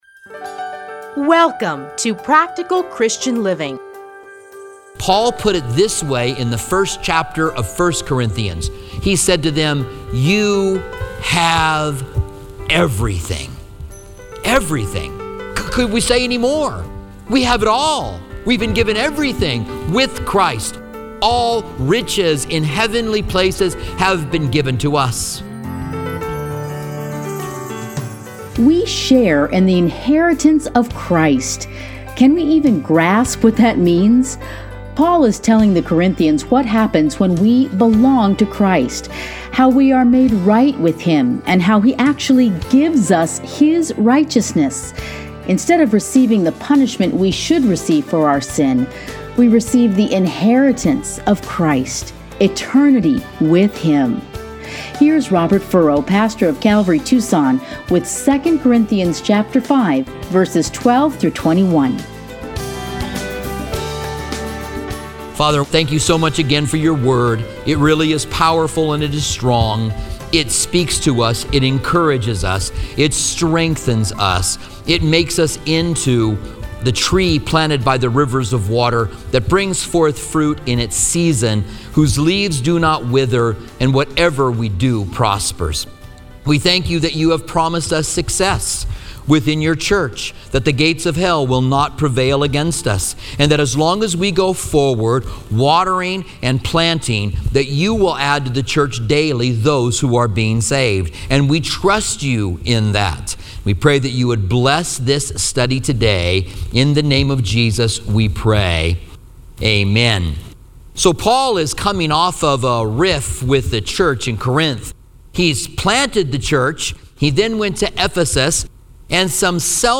Listen here to a teaching from 2 Corinthians.